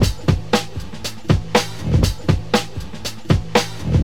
119 Bpm Drum Loop Sample G Key.wav
Free drum groove - kick tuned to the G note. Loudest frequency: 1137Hz
119-bpm-drum-loop-sample-g-key-7VT.ogg